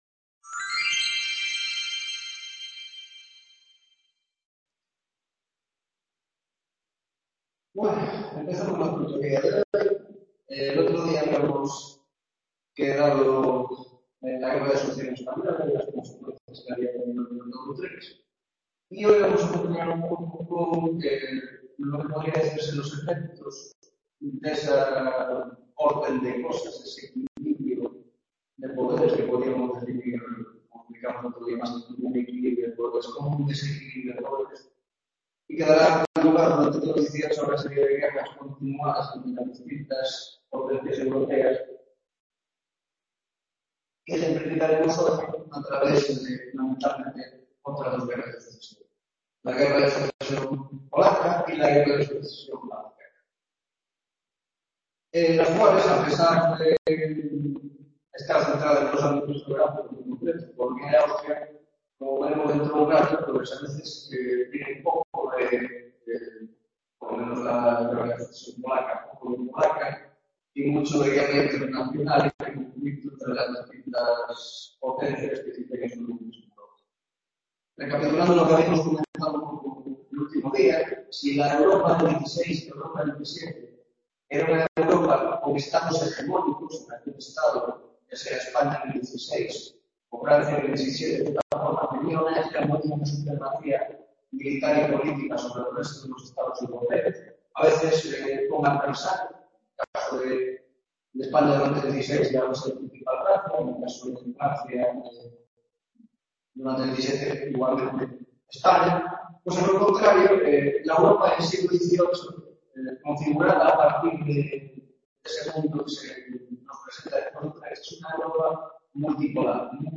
10ª tutoria de Historia de la Baja Edad Moderna - Guerra de Sucesión Austria y Polonia y proceso de división de Polonia